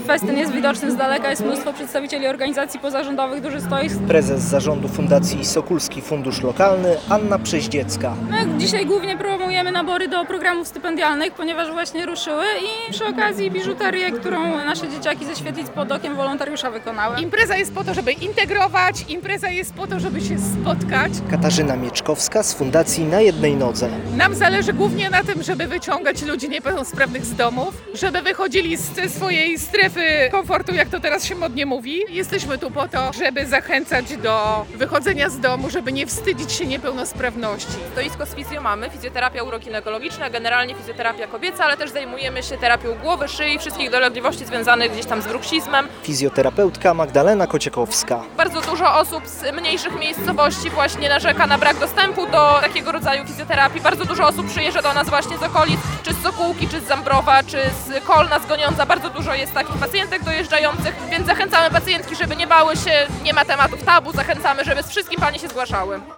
Święto Organizacji Pozarządowych w Sokółce - relacja
Na festynie przed kinem Sokół było mnóstwo stoisk poszczególnych fundacji i stowarzyszeń.